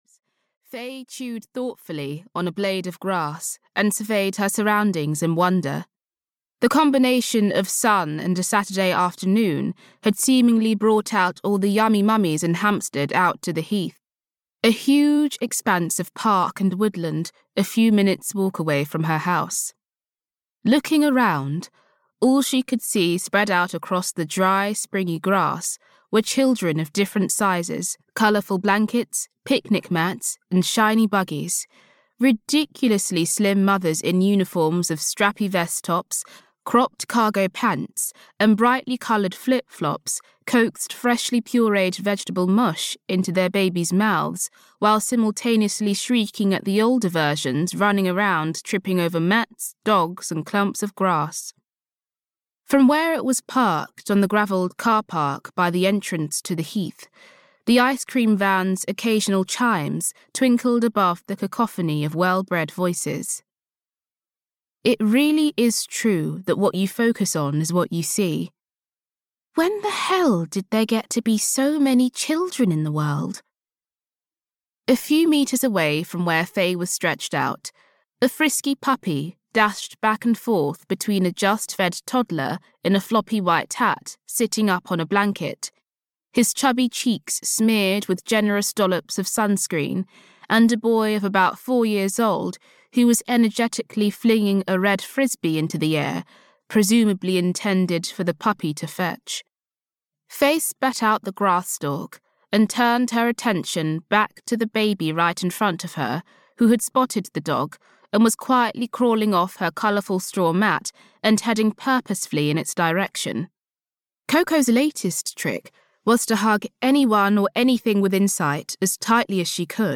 From Pasta to Pigfoot: Second Helpings (EN) audiokniha
Audiobook From Pasta to Pigfoot: Second Helpings, written by Frances Mensah Williams.
Ukázka z knihy